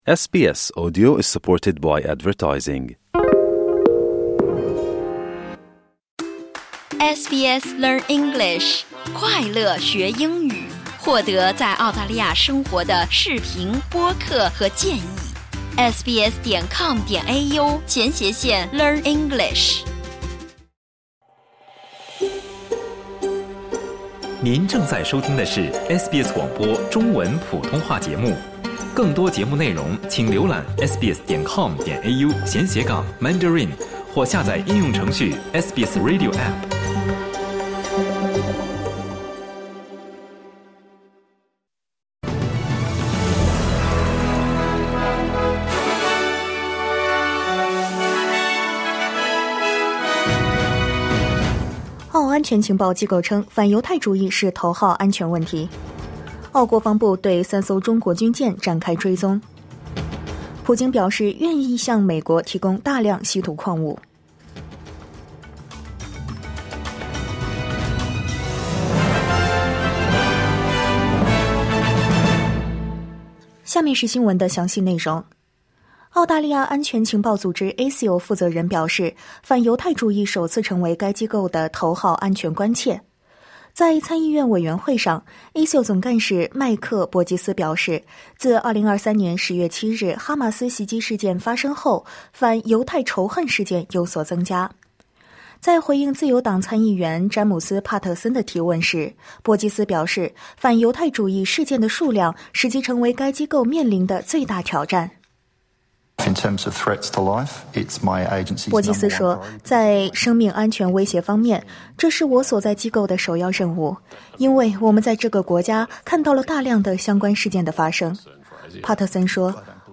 SBS早新闻（2025年2月26日）